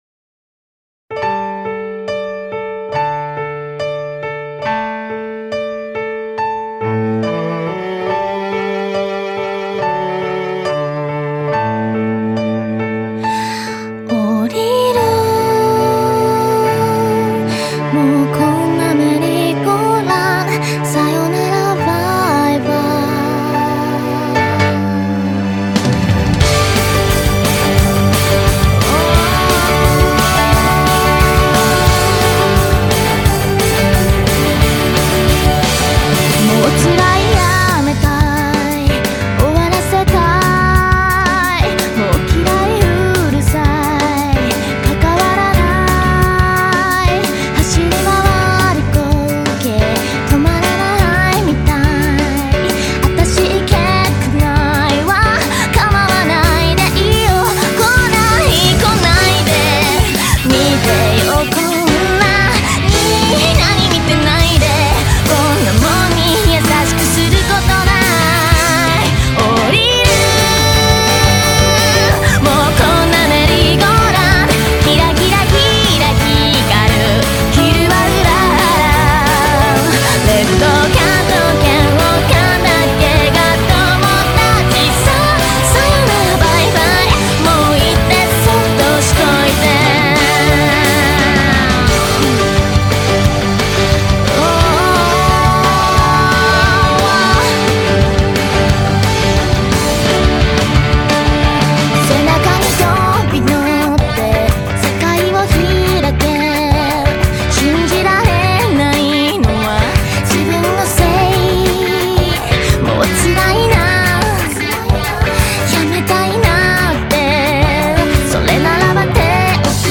una relajante